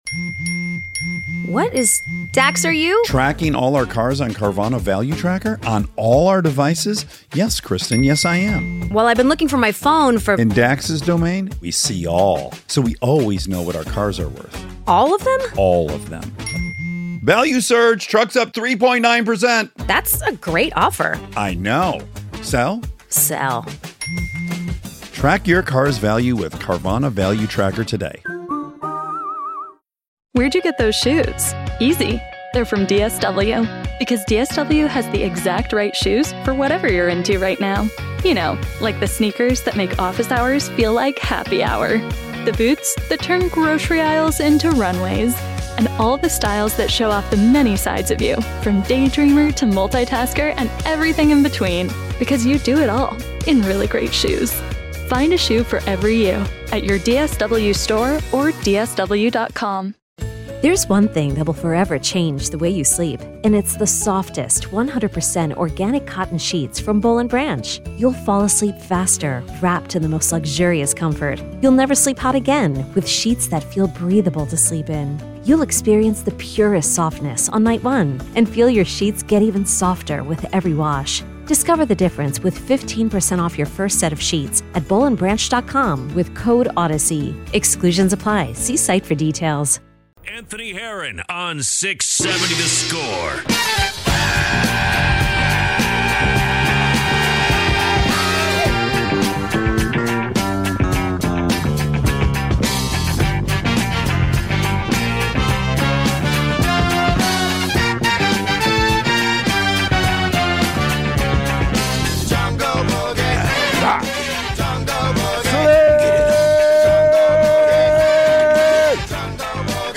670 The Score personalities react to the latest Chicago sports news and storylines.